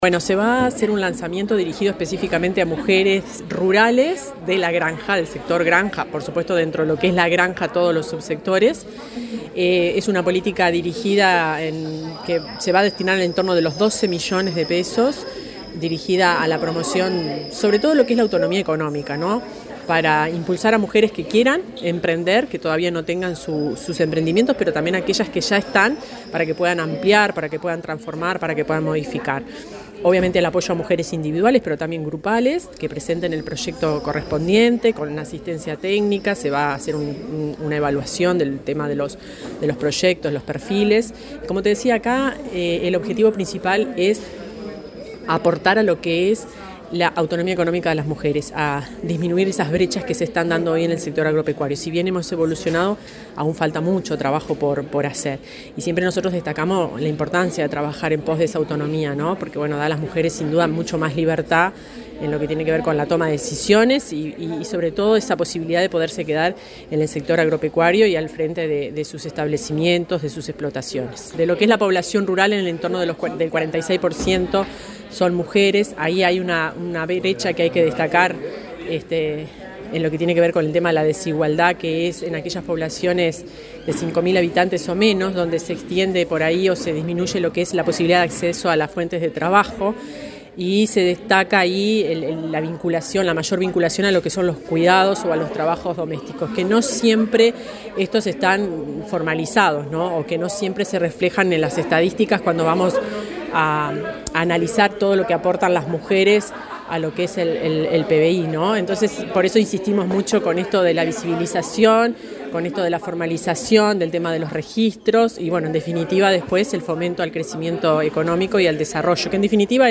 Entrevista a la directora general del MGAP, Fernanda Maldonado